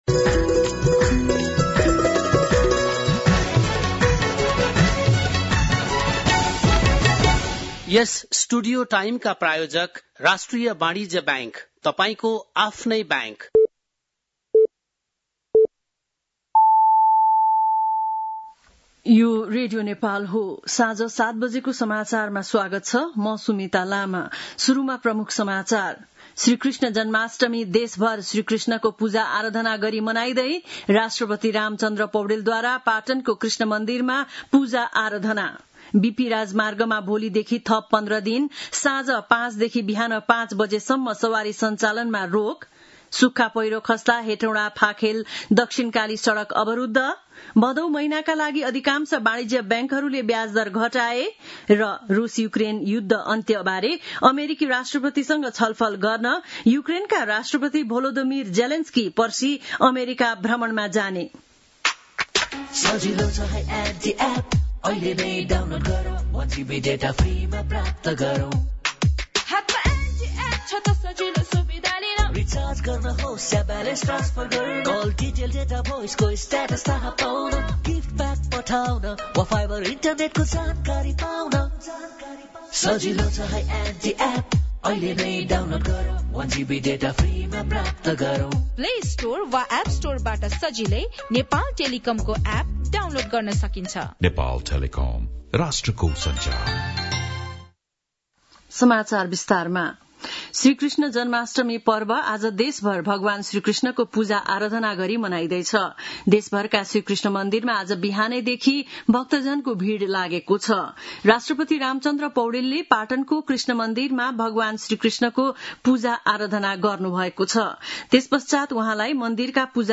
बेलुकी ७ बजेको नेपाली समाचार : ३१ साउन , २०८२
7-PM-Nepali-NEWS-04-31.mp3